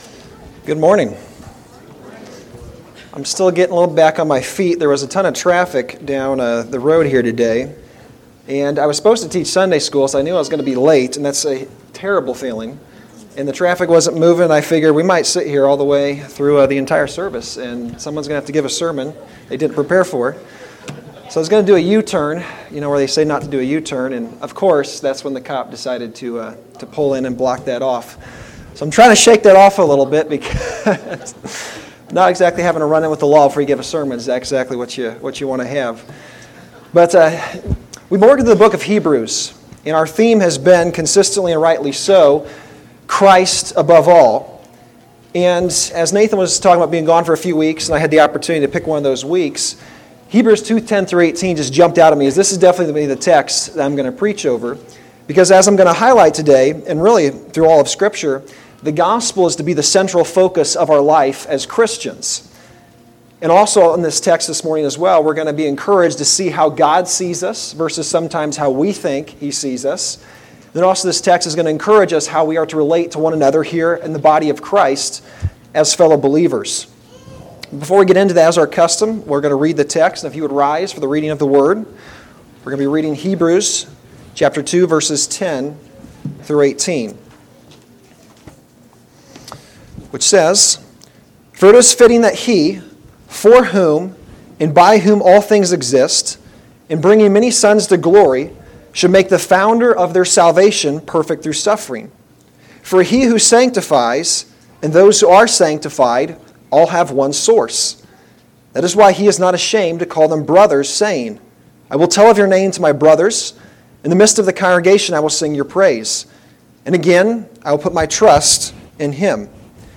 Sermon-6-22-25-Edit.mp3